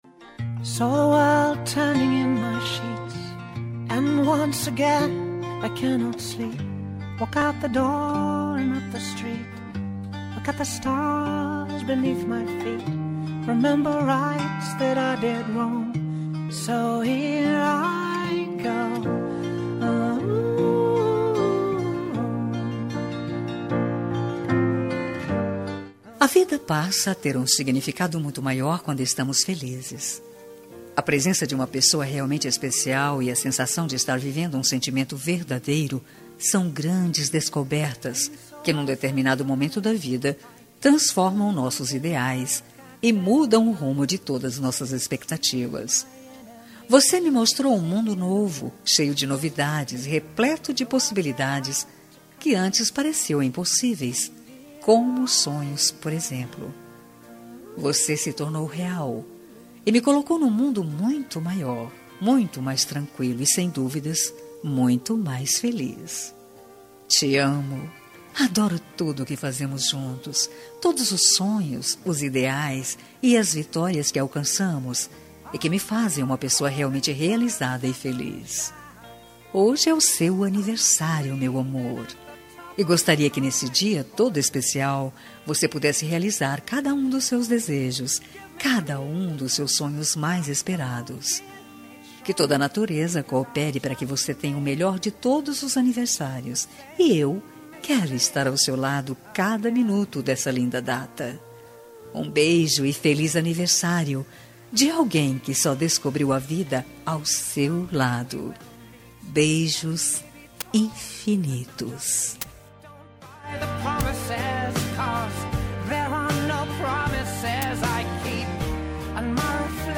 Aniversário Romântico – LGBT – Voz Feminina – Cód: 7001